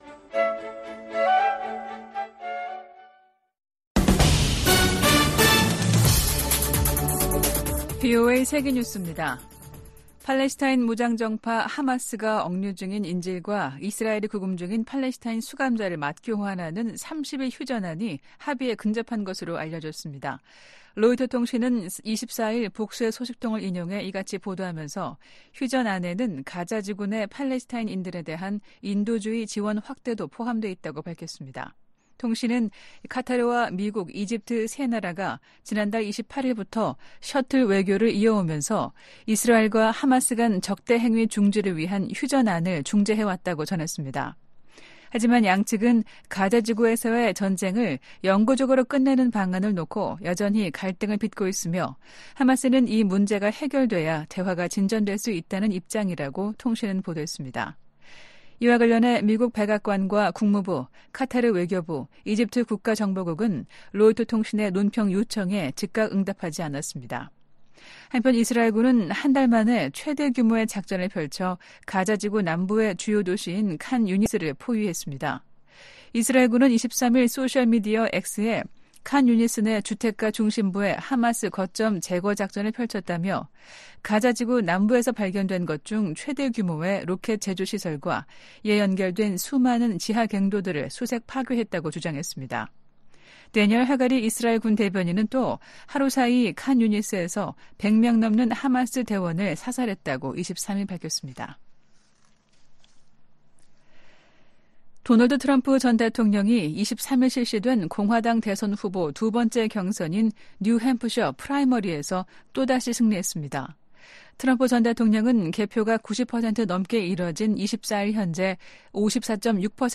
VOA 한국어 아침 뉴스 프로그램 '워싱턴 뉴스 광장' 2024년 1월 25일 방송입니다. 북한이 서해상으로 순항미사일 여러 발을 발사했습니다.